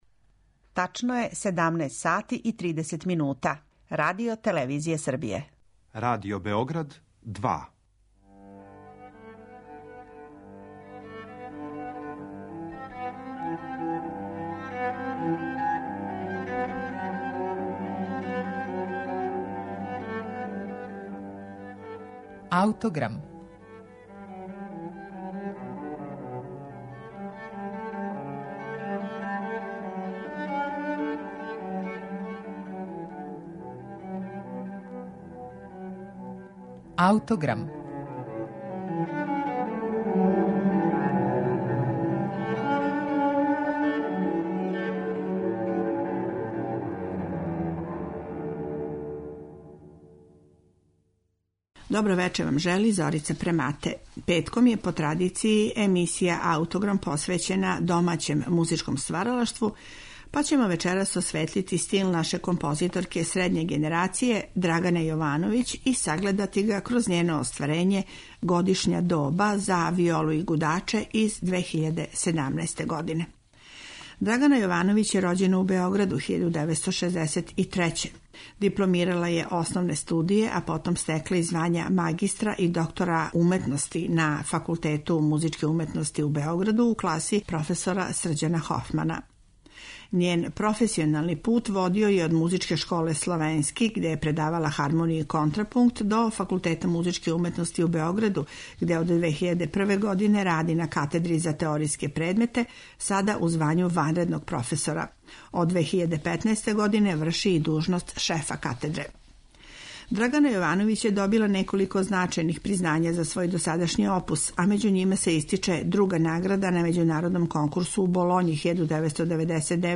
концерт за виолу и гудаче
камерни ансамбл